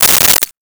Keys Drop 02
Keys Drop 02.wav